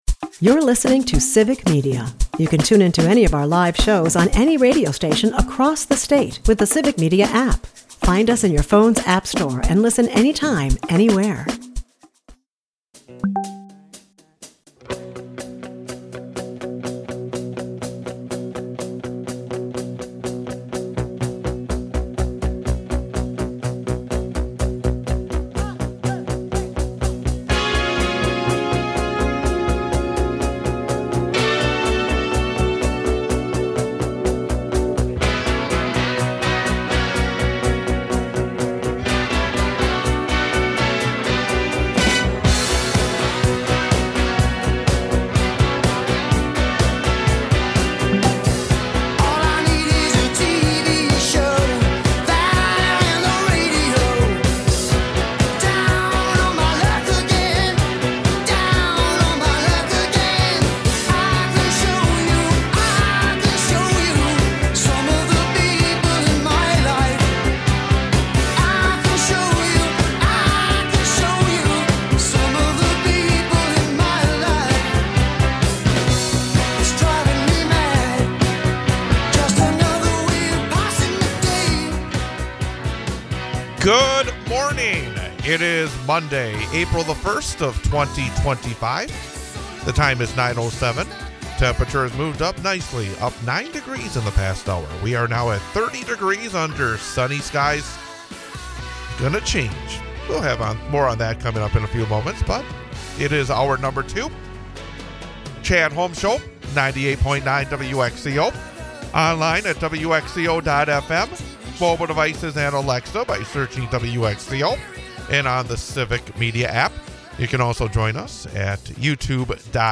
Also time for the Brewers to get moving after a third straight embarrassing loss. We bring back yesterday's discussion with Judge Susan Crawford, candidate for the state Supreme Court.